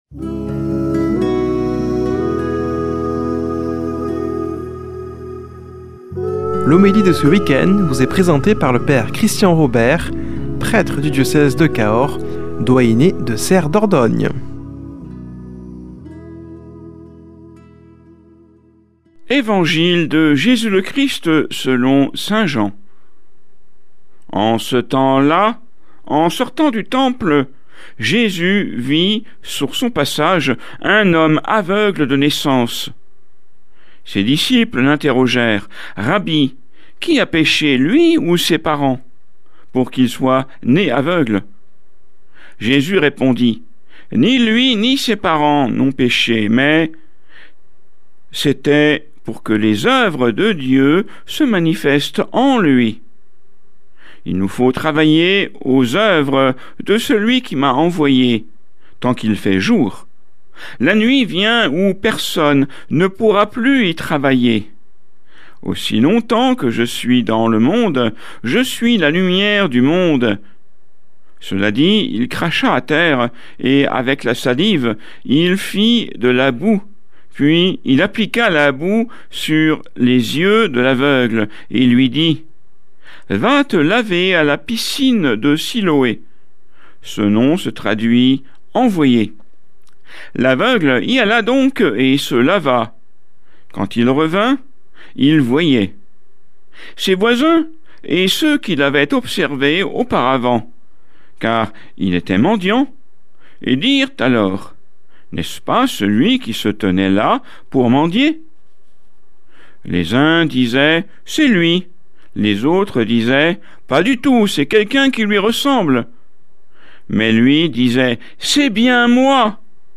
Homélie du 14 mars